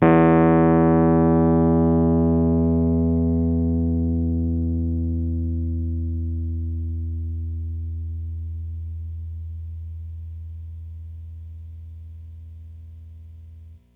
RHODES CL04R.wav